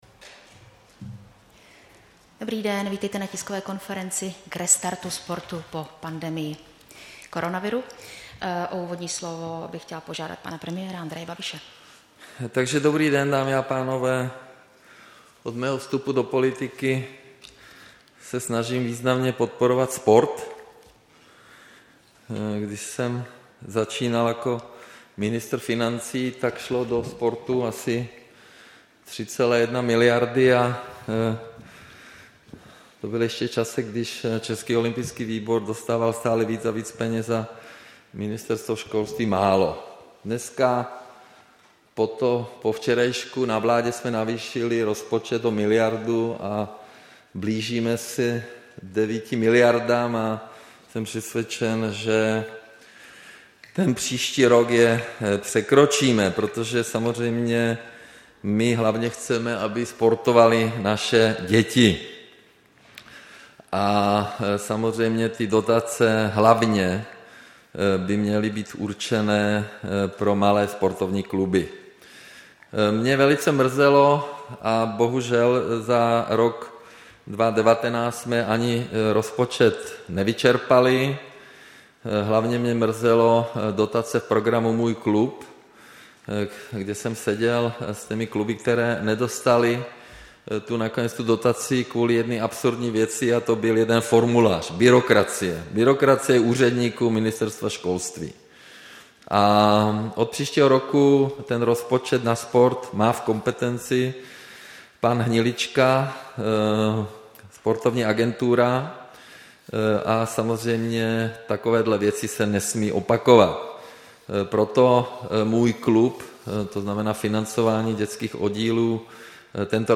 Tisková konference k dotačnímu programu COVID – Sport, 2. června 2020